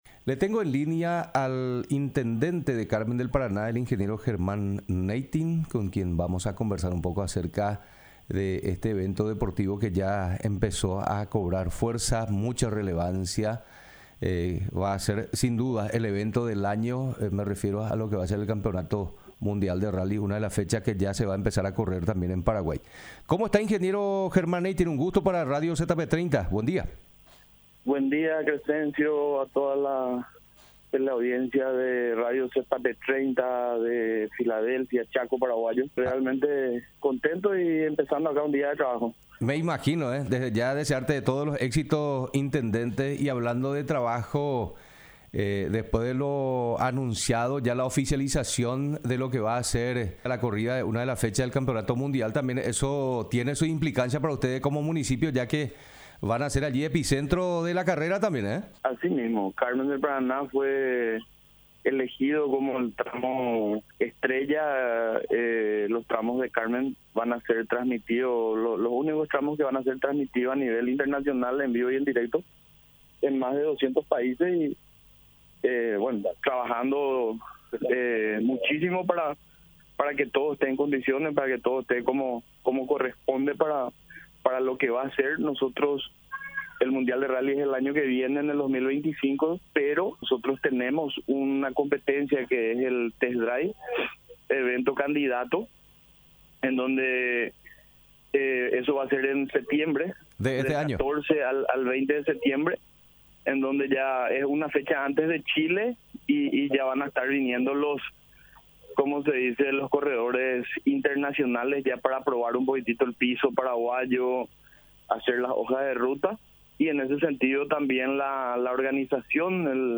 Entrevistas / Matinal 610 Organización previa al mundial del Rally Jul 23 2024 | 00:18:34 1x 00:00 / 00:18:34 Subscribe Share RSS Feed Share Link Embed